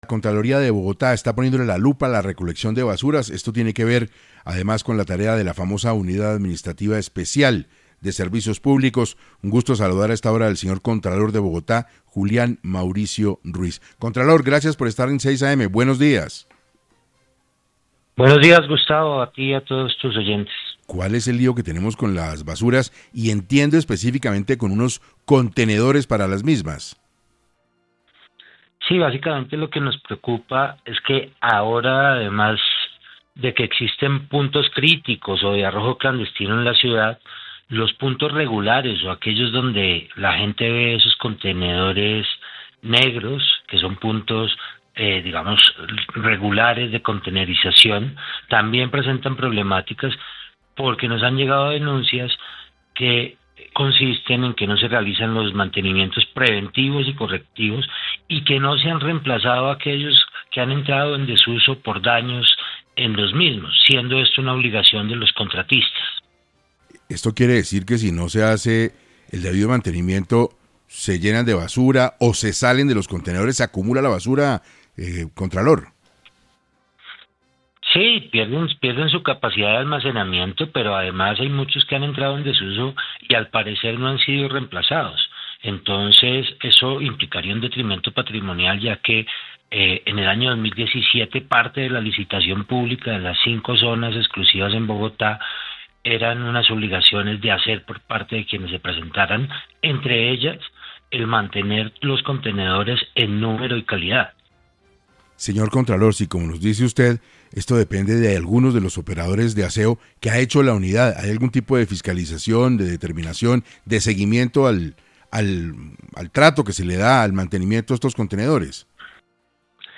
Julián Mauricio Ruíz, contralor de Bogotá, habló en 6AM sobre las irregularidades que detectaron en la UAESP.
Por tal motivo, en 6AM de Caracol Radio estuvo Julián Mauricio Ruíz, contralor de Bogotá, para hablar un poco sobre lo que está pasando con el tema de las basuras en la ciudad y las irregularidades que detectaron en la Unidad Administrativa Especial de Servicios Públicos (UAESP).